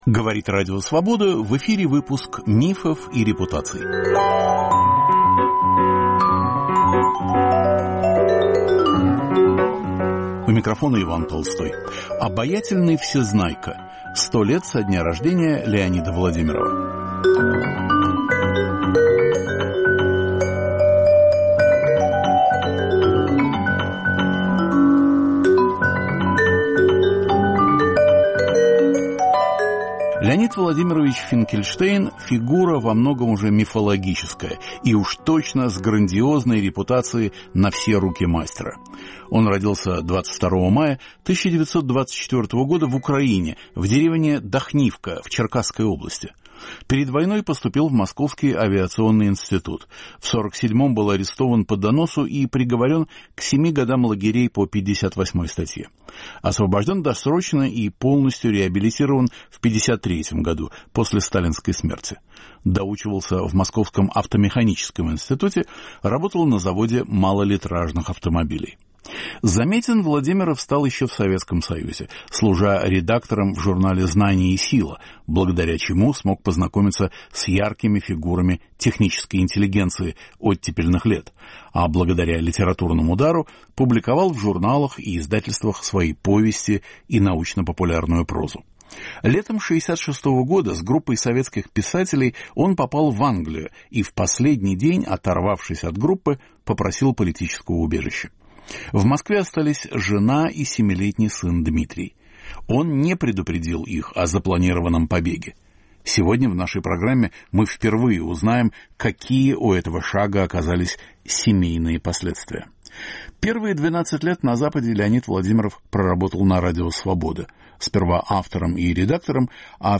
Ведущий Иван Толстой поднимает острые, часто болезненные, вопросы русского культурного процесса: верны ли устоявшиеся стереотипы, справедливы ли оценки, заслуженно ли вознесены и несправедливо ли забыты те или иные деятели культуры?